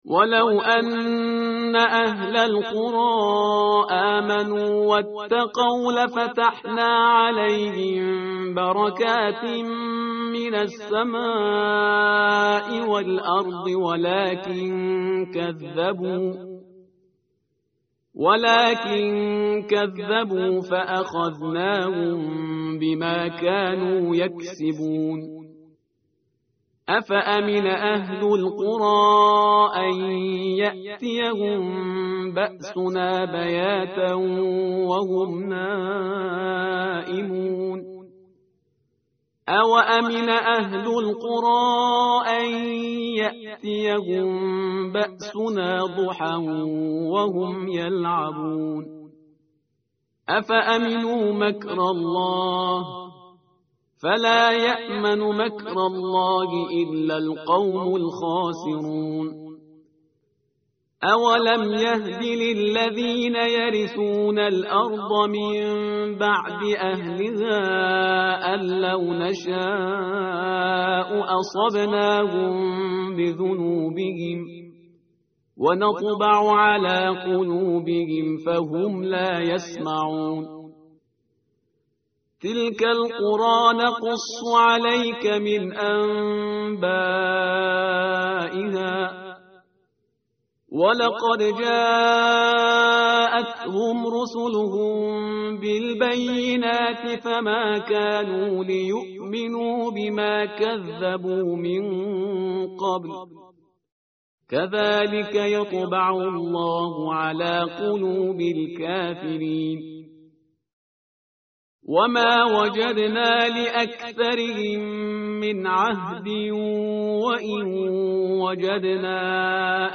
tartil_parhizgar_page_163.mp3